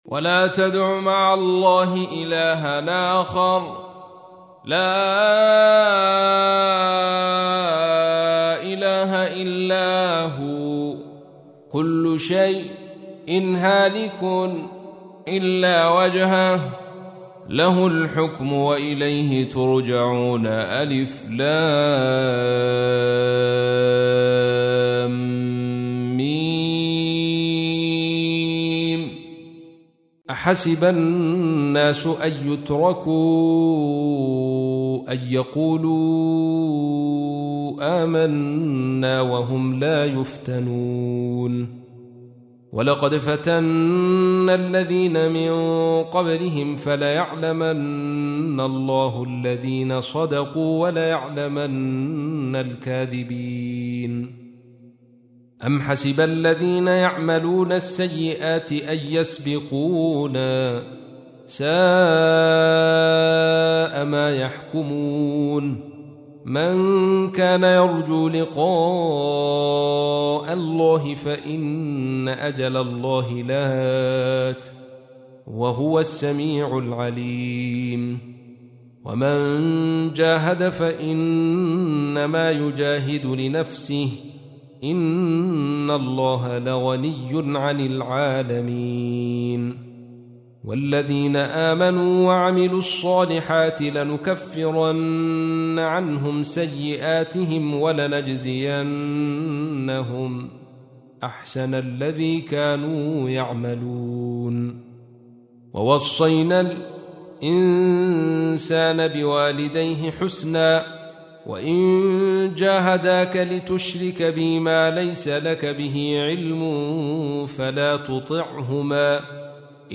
تحميل : 29. سورة العنكبوت / القارئ عبد الرشيد صوفي / القرآن الكريم / موقع يا حسين